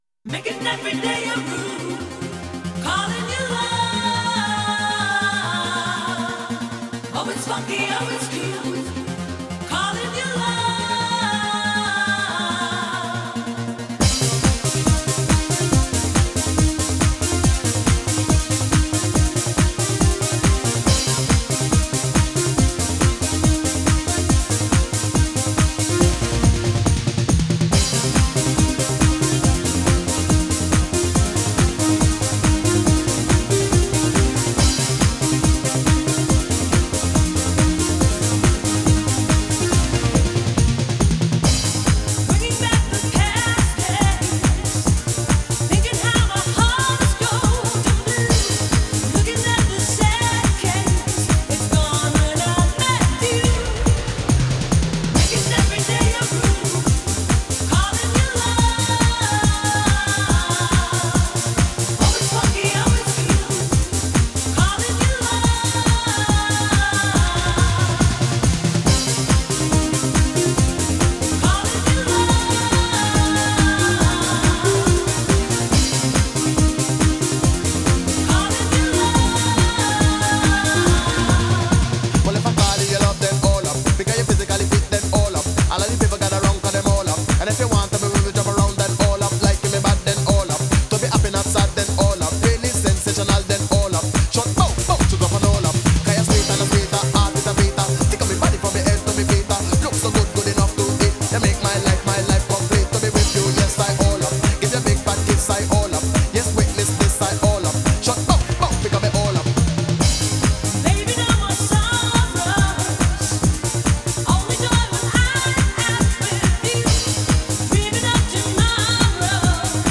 Genre: Italodance.